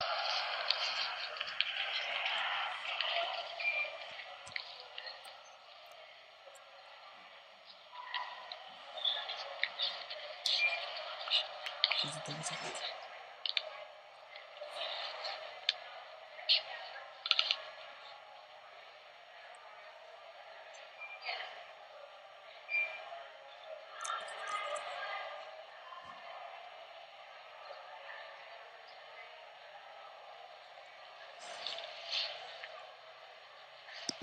Location: Hofstra University BookStore
Sounds: People Talking, feet shuffling, cash register, bag rustling, people laughing,